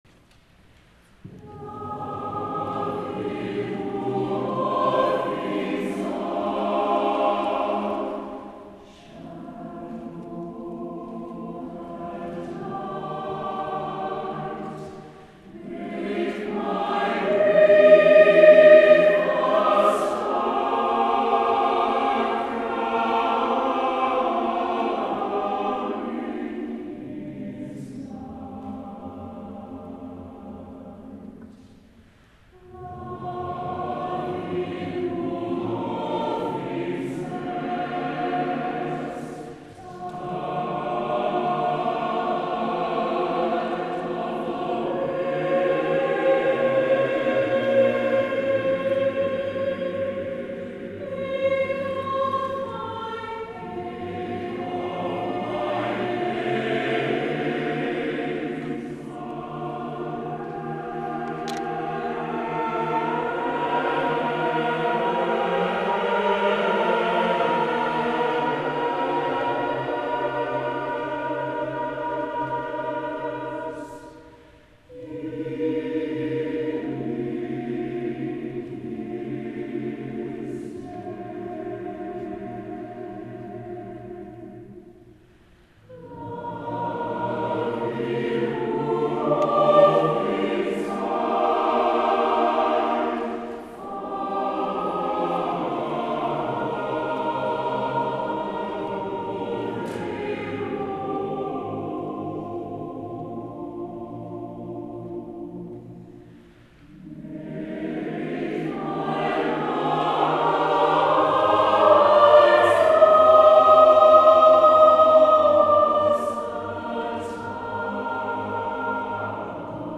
SATB a cappella
premiere audio (excerpt):